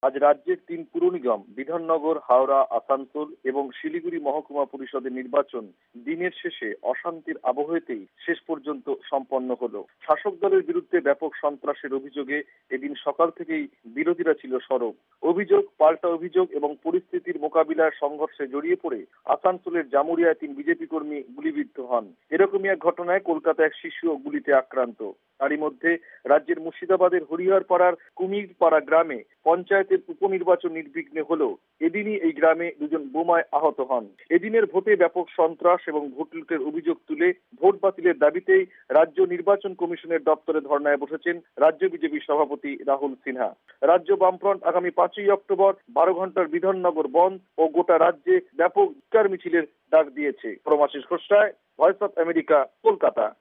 এরই ওপর রিপোর্ট পাঠিয়েছেন কলকাতা থেকে